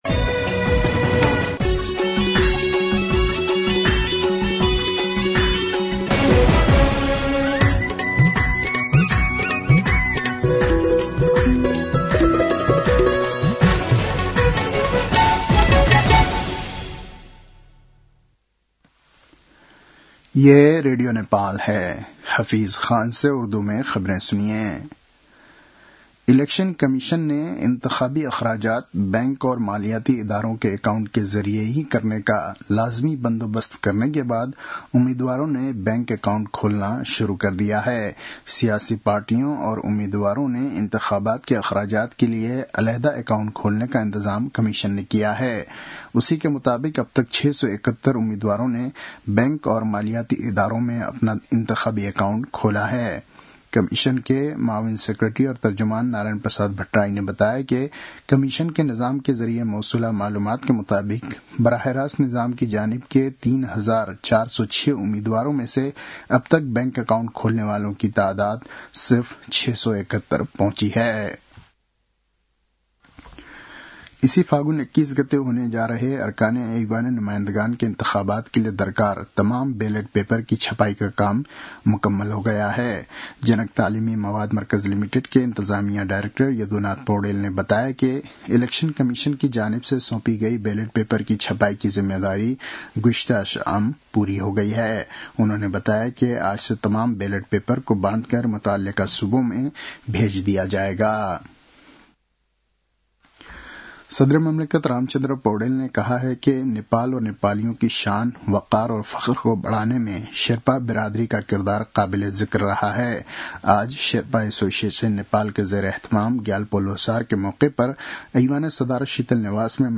उर्दु भाषामा समाचार : ५ फागुन , २०८२